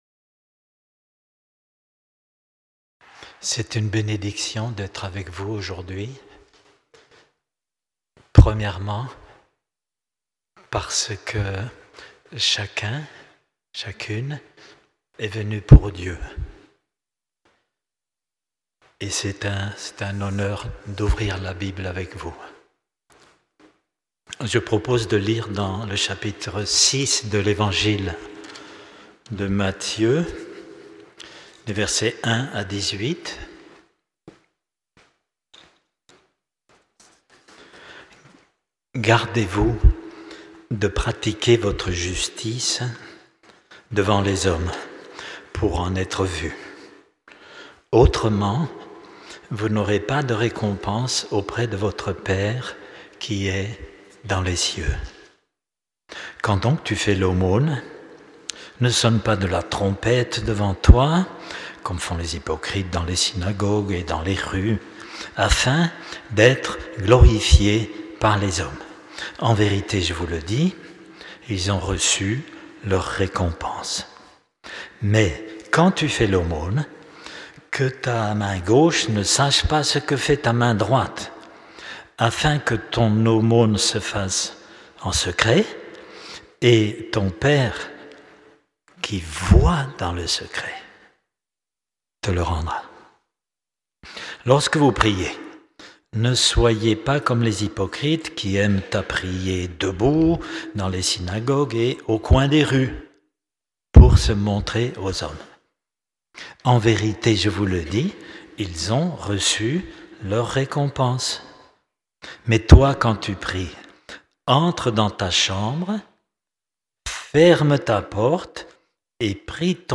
Prédication du 01 mars 2026.